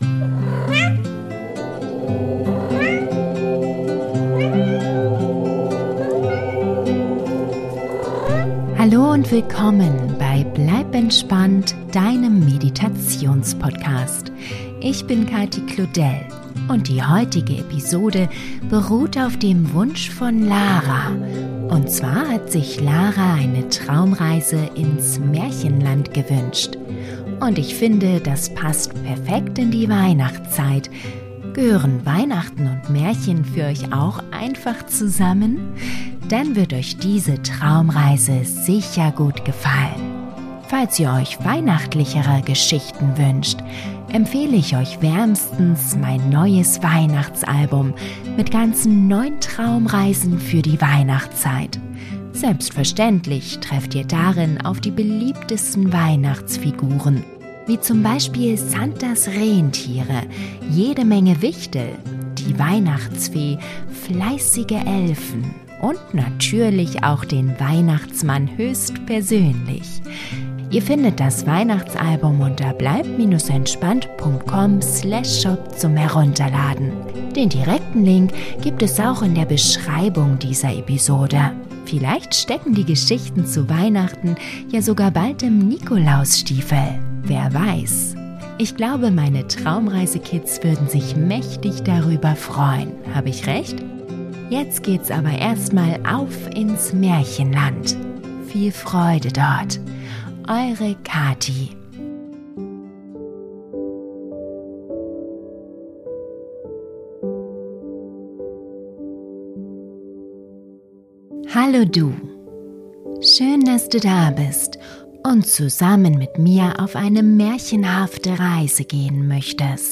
Traumreise für Kinder, Jugendliche & Erwachsene: Das magische Märchenbuch - Märchengeschichte ~ Bleib entspannt!